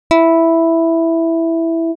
• 上記の速度波形を音声データとして書き出したもの
振幅特性を見ると一次固有振動数の整数倍の周波数にピークが立っていることが確認でき、聴覚的にも単一の音程を持つ音が生成されています。